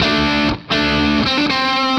Index of /musicradar/80s-heat-samples/120bpm
AM_HeroGuitar_120-D02.wav